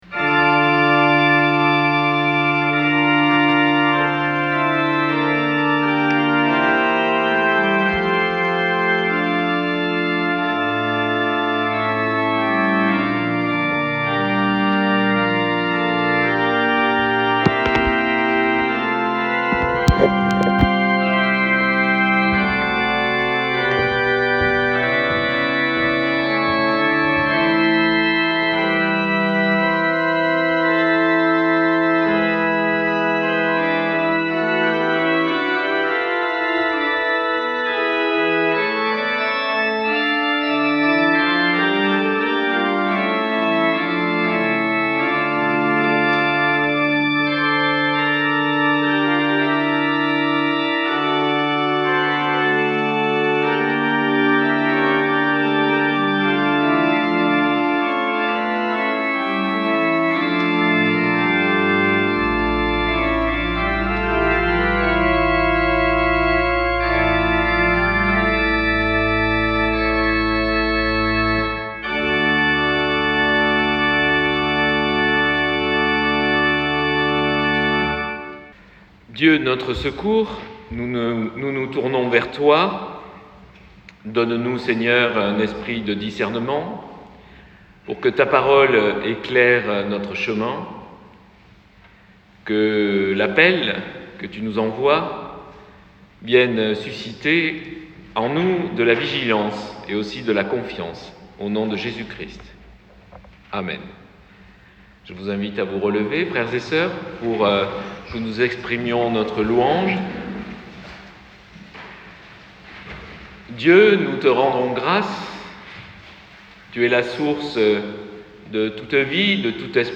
Extraits du culte du 22 décembre 2024.mp3 (76.65 Mo)